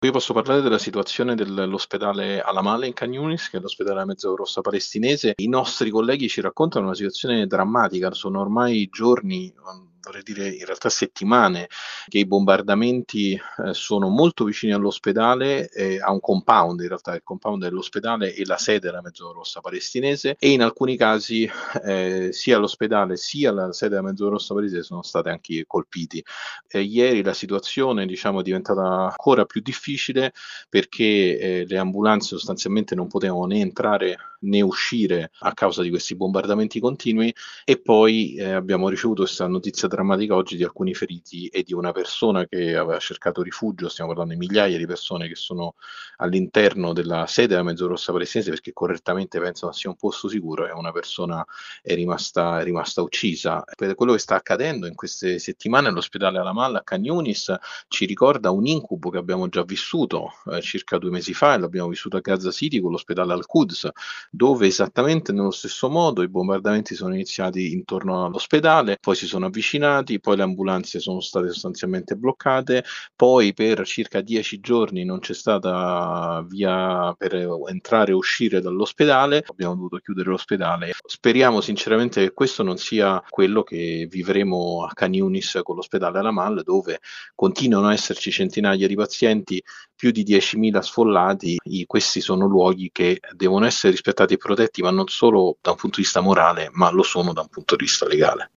Il racconto della giornata di martedì 23 gennaio 2024 con le notizie principali del giornale radio delle 19.30. I bombardamenti nel sud della striscia di Gaza in queste ore si sono intensificati e l’esercito israeliano ha completamente accerchiato la città di Khan Younis.